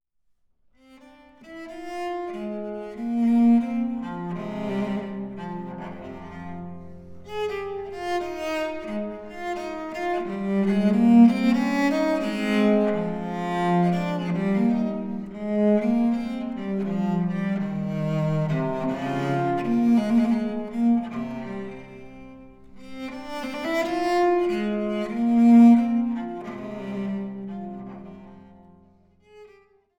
Gambenmusik - zahlreiche Ersteinspielungen
Diskant- und Bassgambe